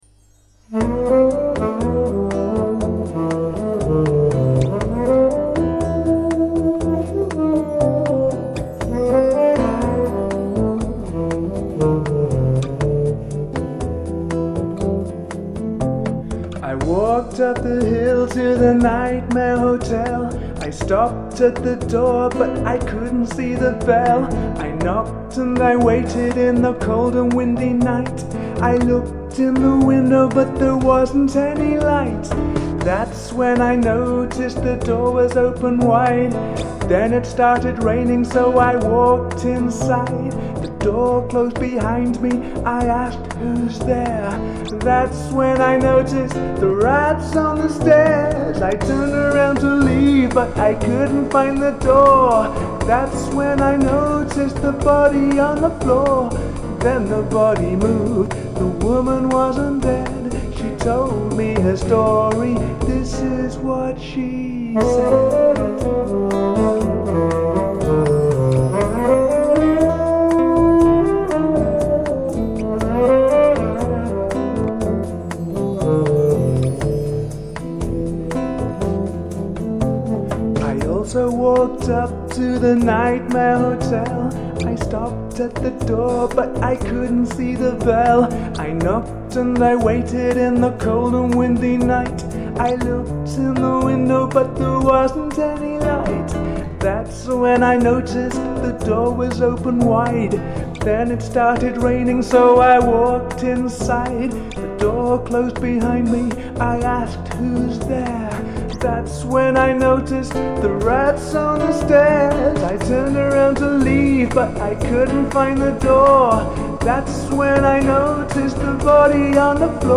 Nightmare hotel song.mp3